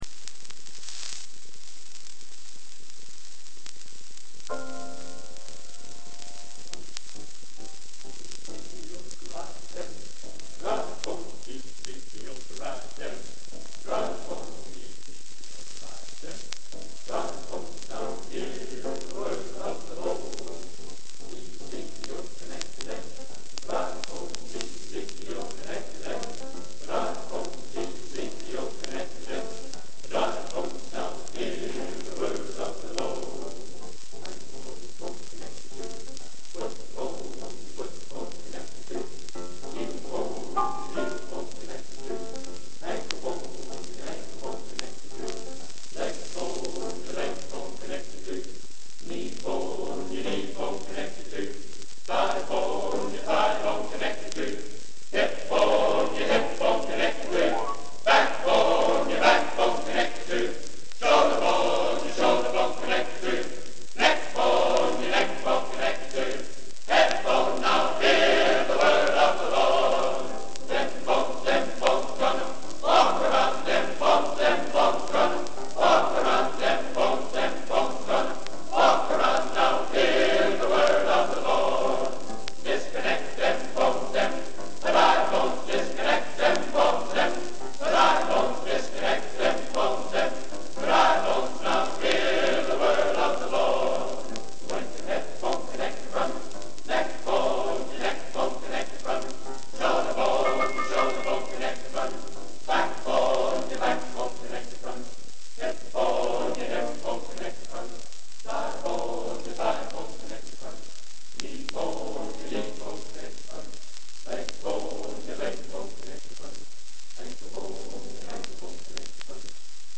Genre: Spiritual | Type: Studio Recording